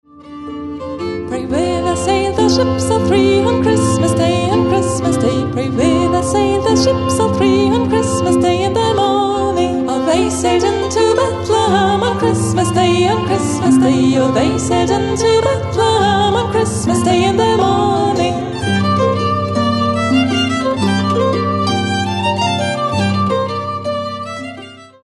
Advents- und Weihnachtsmusik